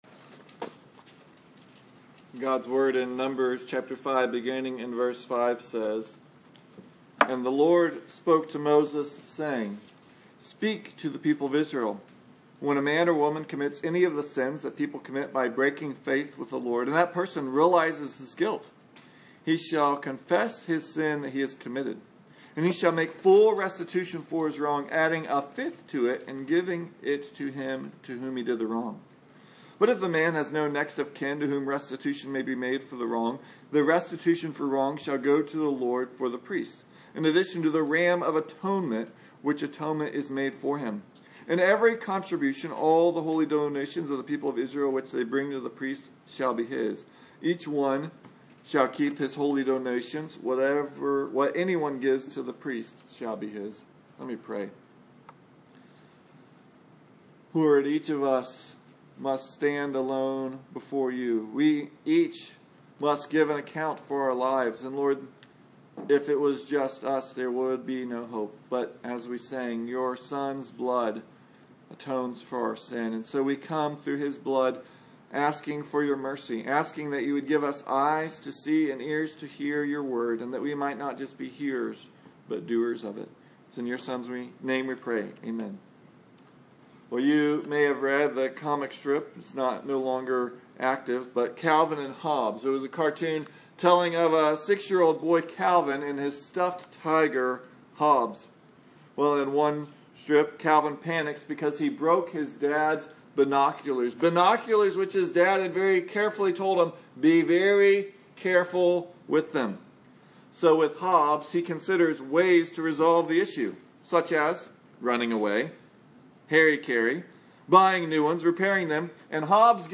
Numbers 5:5-10 Service Type: Sunday Morning God instructs Israel what to do when they sin.